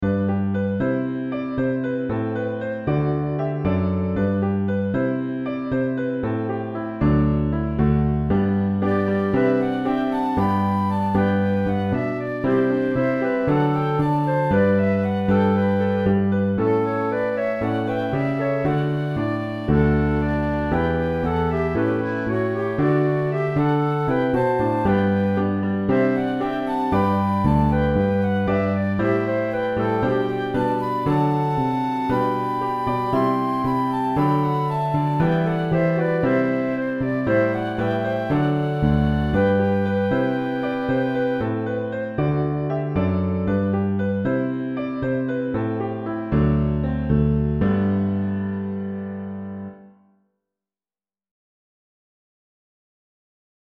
third pair) all feature a flute sound, they can be played by any instrument fitting for worship.